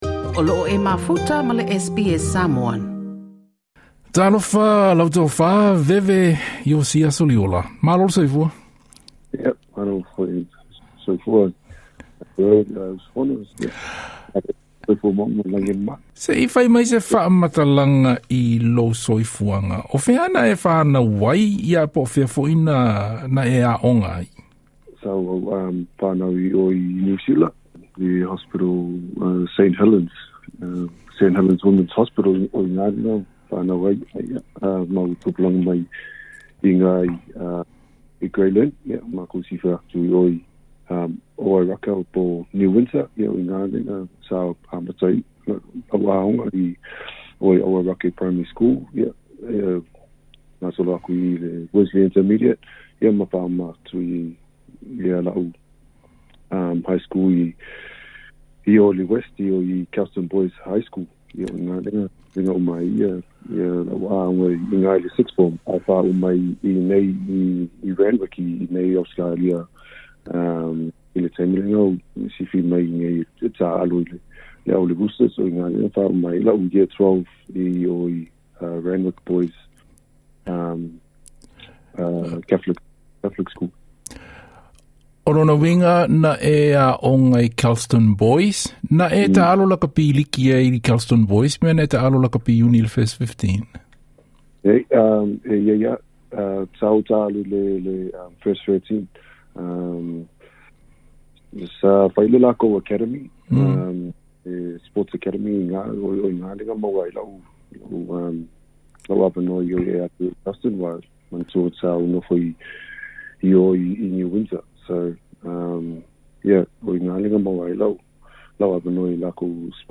Mai le Kolisi a Kelston Boys i Aukilani, i le Sydney Roosters i le NRL, St Helens i le Supa Liki i Egelani ae faai'u i le Canberra Raiders. O se talanoaga lenei ma le Tofa Veve Iosia Soliola i lana galuega i le taimi nei i le fautuaina ma lagolago i tagata (alii ma tamaita'i) o loo ta'a'alo i le Canberra Raiders.